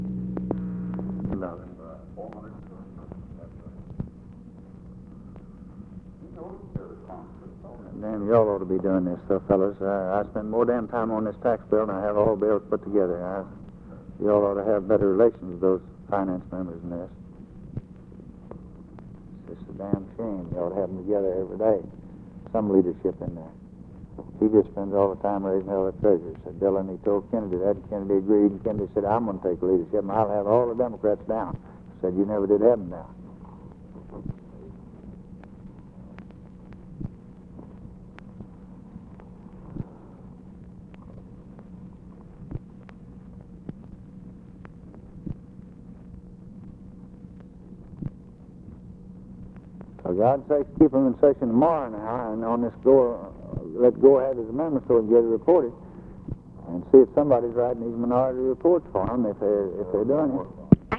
OFFICE CONVERSATION, January 23, 1964